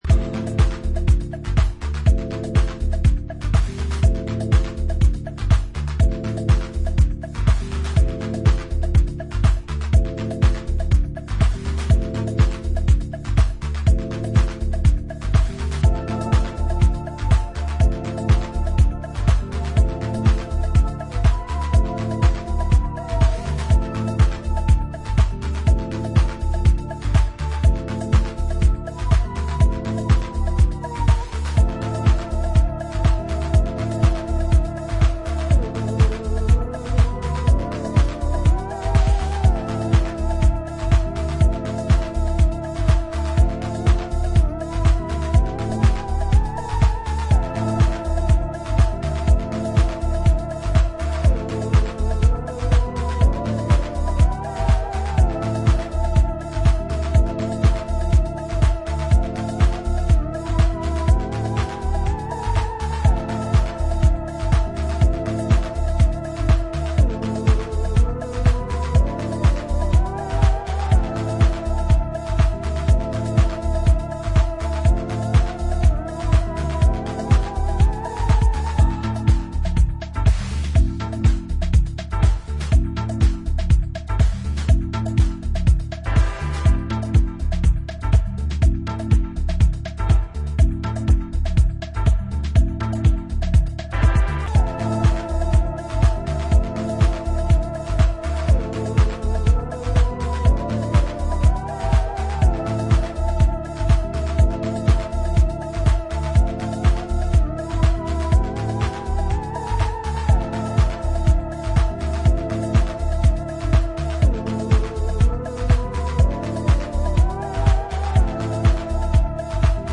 forward thinking electronic music
House Bass